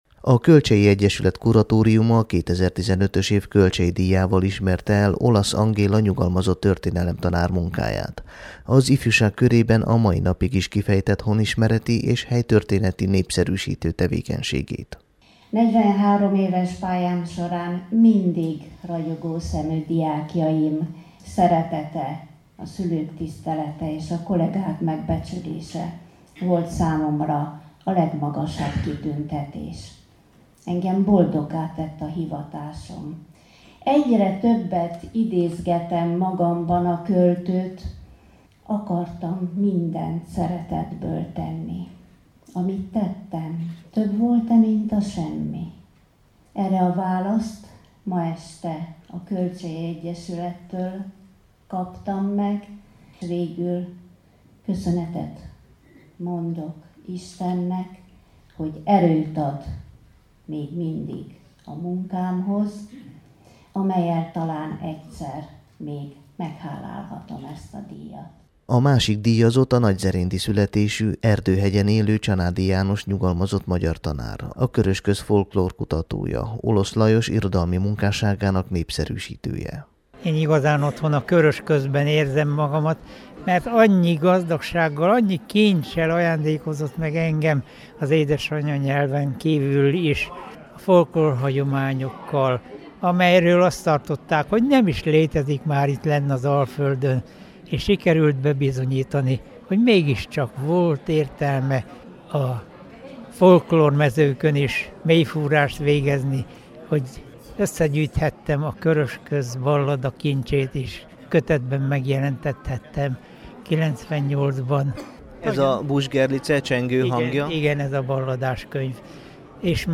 beszámolója